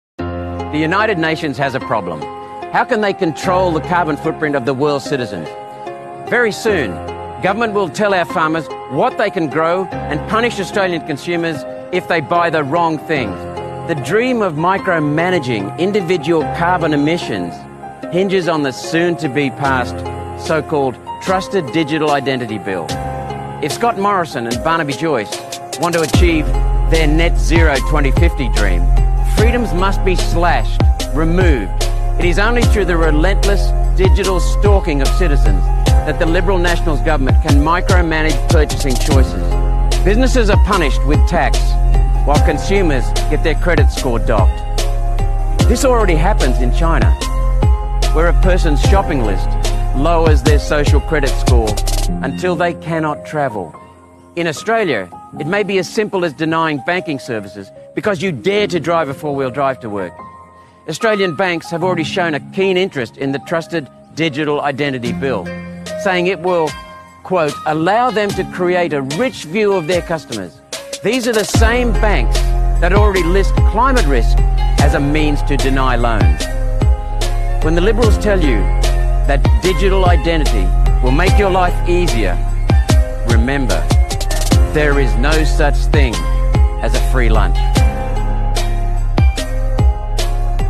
Senator Malcolm Roberts talking about net zero and digital IDs.
Watch Queensland Senator Malcolm Roberts talking about net zero and digital IDs.